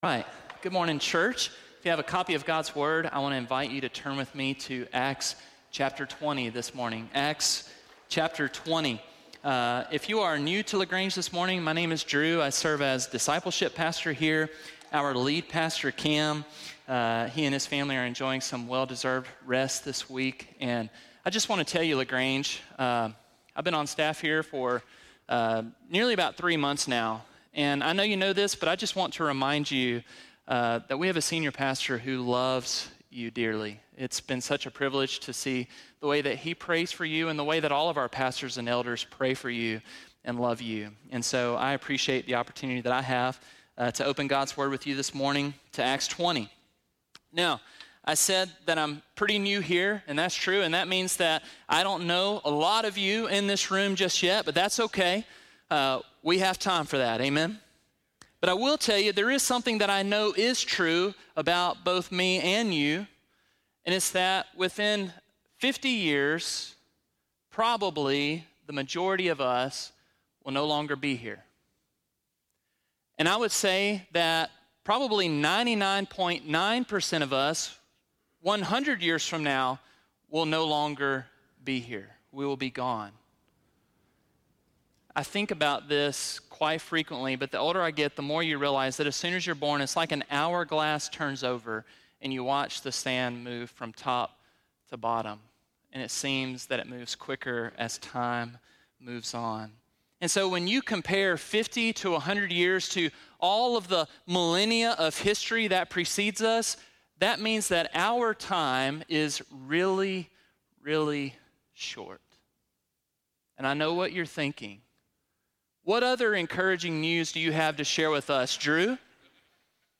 10.20-sermon.mp3